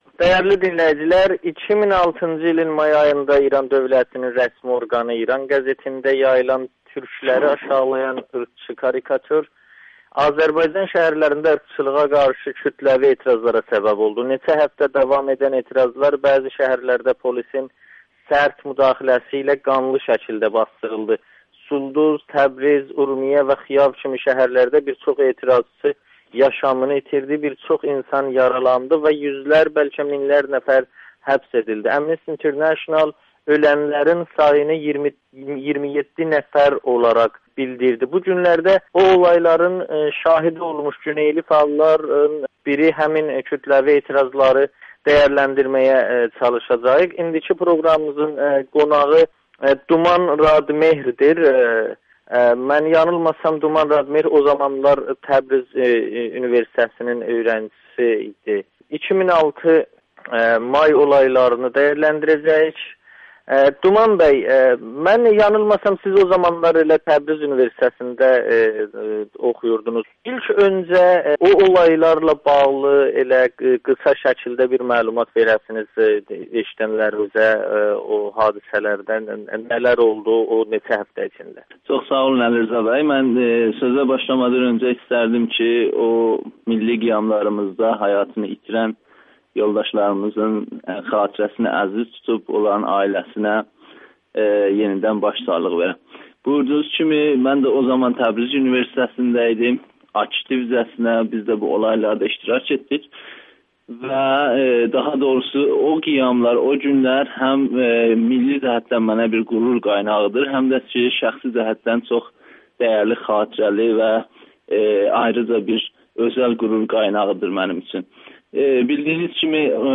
2006 may qiyamında iştirak etmiş güneyli fəal Amerikanın Səsinə danışır